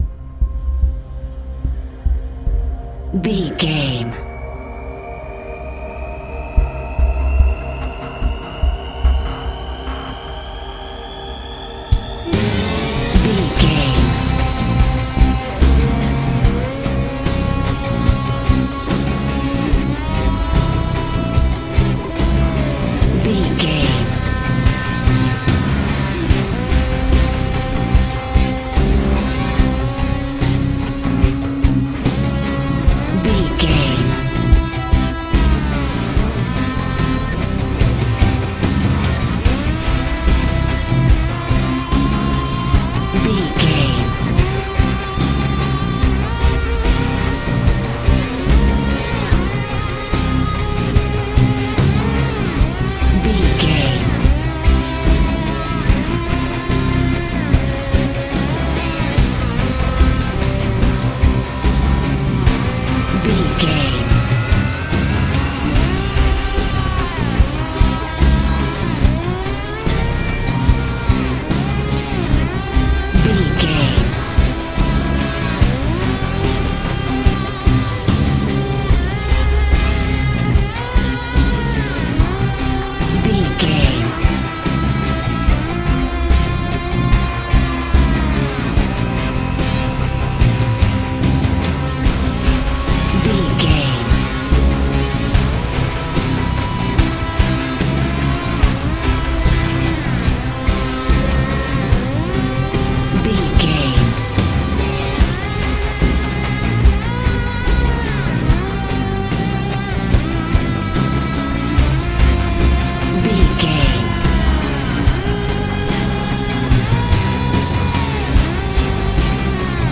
Thriller
Aeolian/Minor
synthesiser
haunting